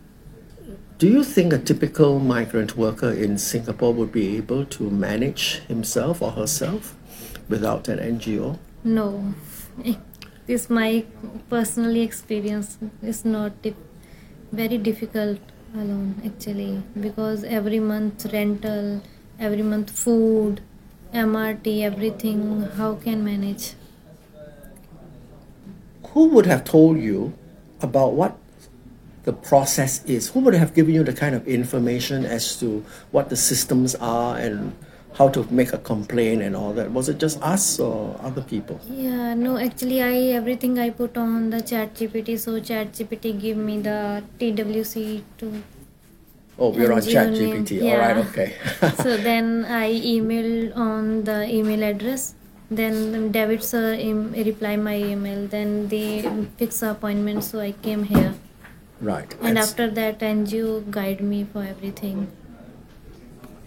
TWC2 bought her a flight ticket – she had no money, and with TEPs, the employer is not responsible for repatriation – and we sat with her one more time, a week before the payment deadline of 15 January, for an exit interview.